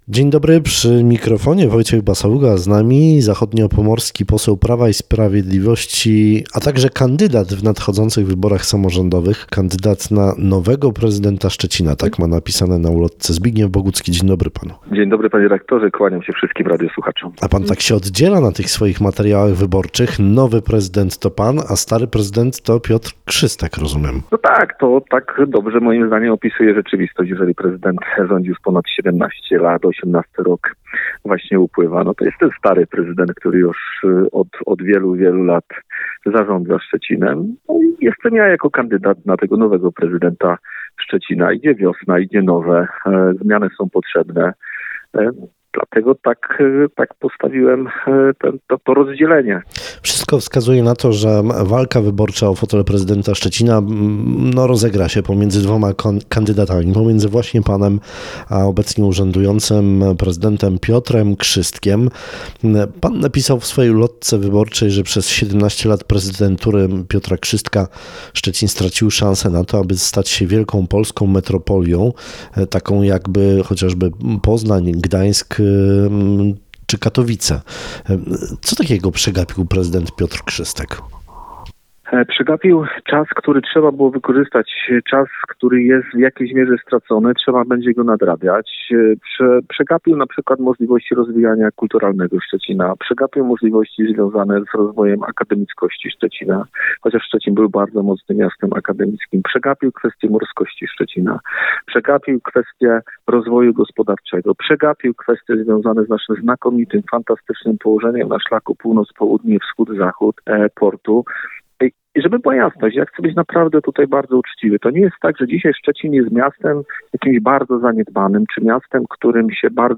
Zbigniew Bogucki – kandydat na prezydenta Szczecina był w piątek gościem Rozmowy Dnia w Twoim Radiu. Polityk mówił o swoim programie wyborczym a także podsumował 17 lat prezydentury Piotra Krzystka.